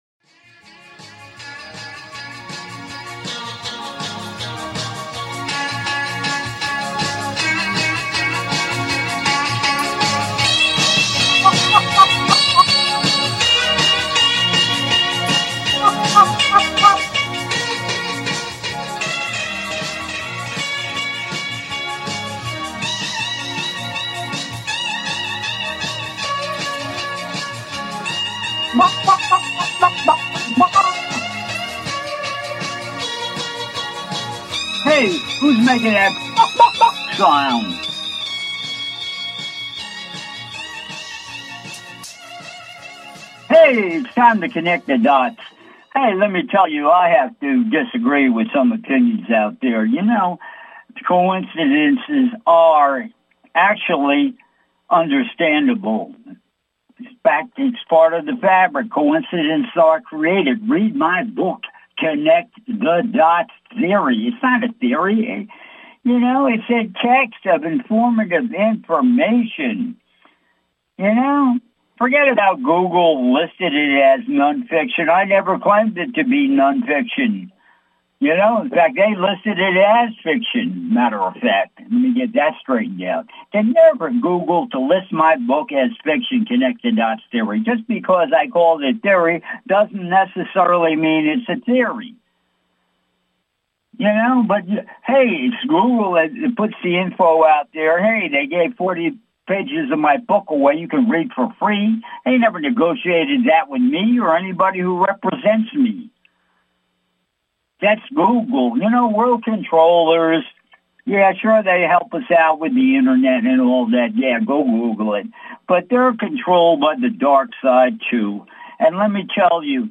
Talk Show Episode, Audio Podcast
"CONNECT THE DOTS" is a call in radio talk show